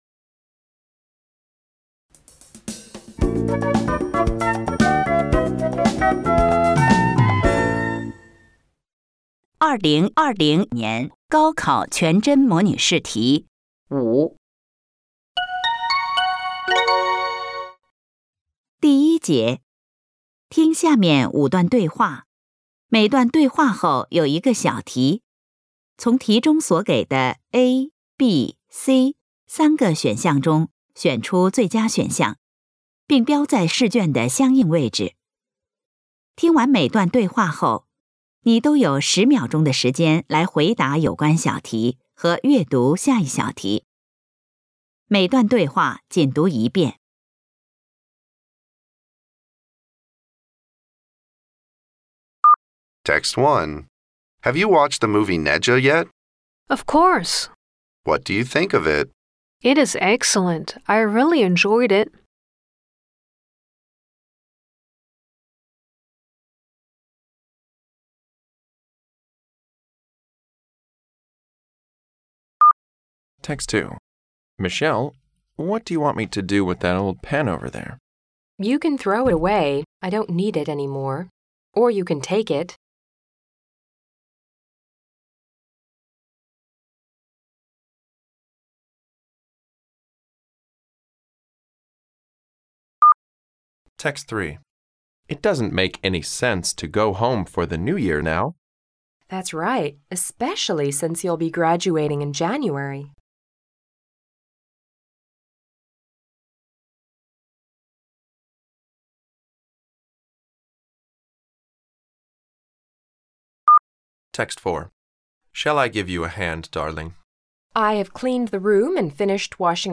高三英语 周考《阶段性检测英语科》 听力